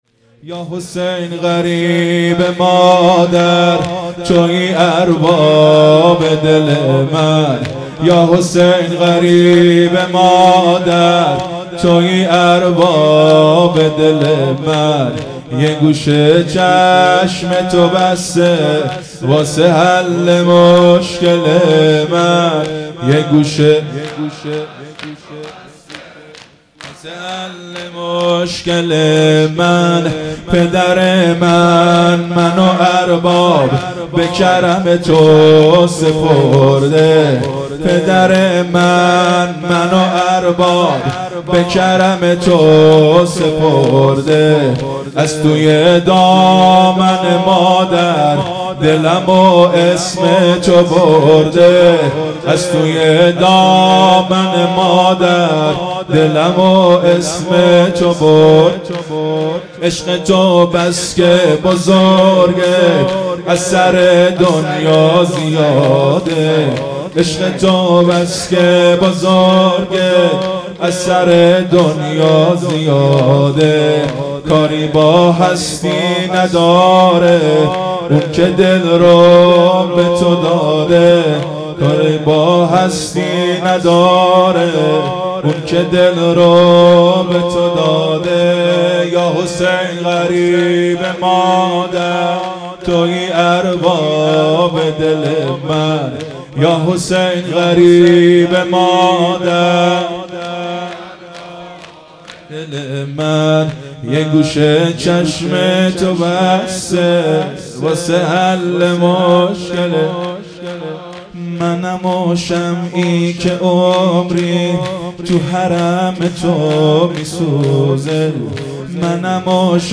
شور | یاحسین غریب مادر مداح
محرم1442_شب ششم